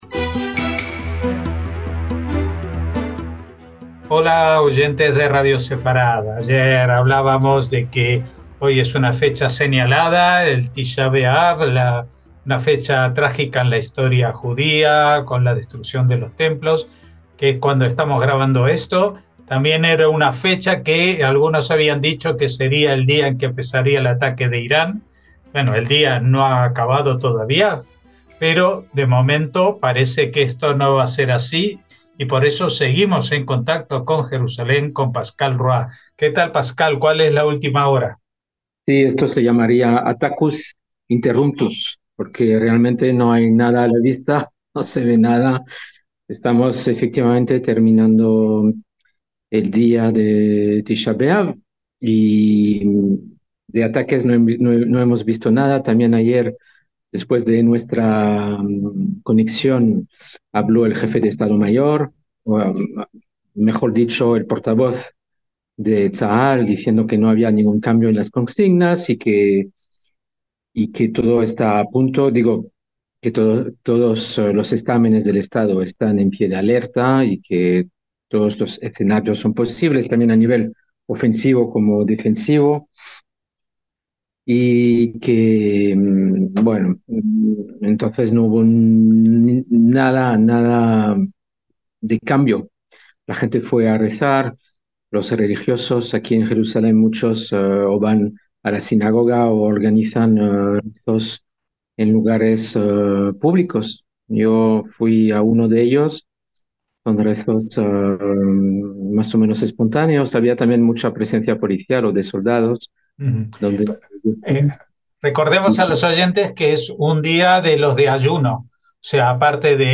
NOTICIAS CON COMENTARIO A DOS - Son las últimas horas de un día especial, que marca una jornada de duelo religioso, 9 del mes de Ab.